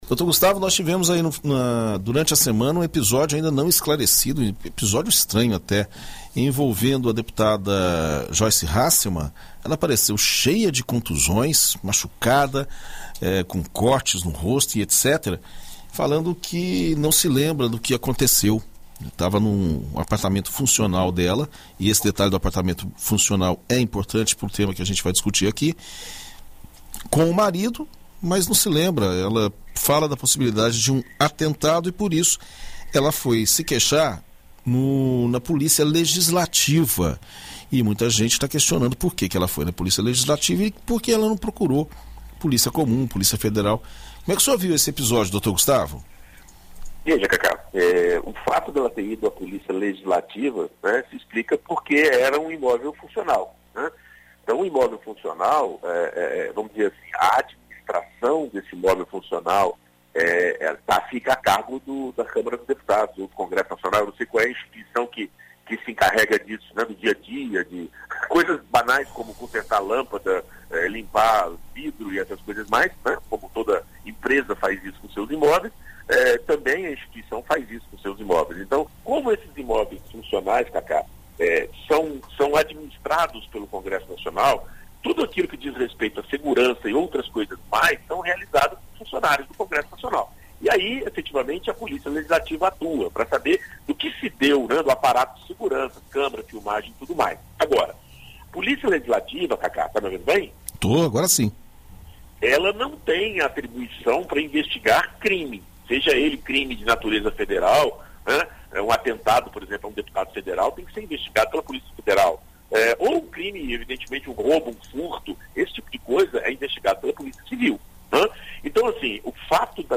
Na coluna Direito para Todos desta segunda-feira (26), na BandNews FM Espírito Santo